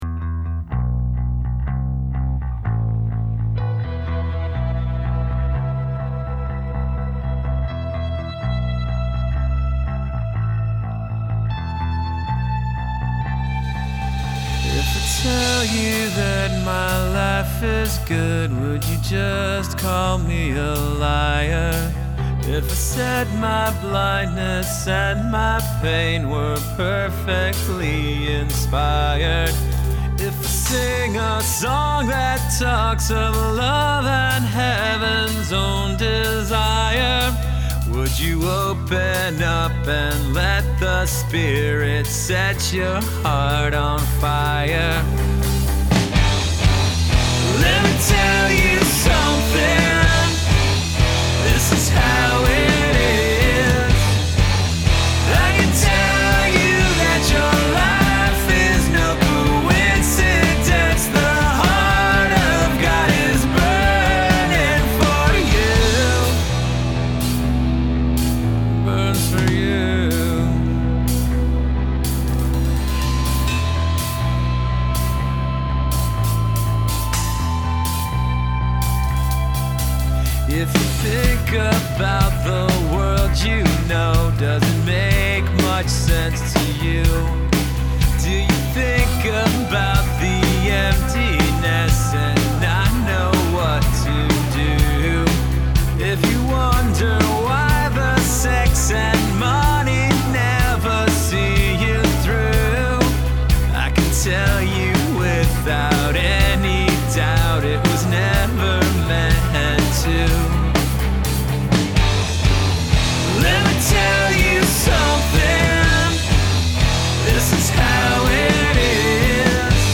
Vocals, Acoustic Guitars, Some Electric Guitars, Bass
Drums, Some Electric Guitars, Bass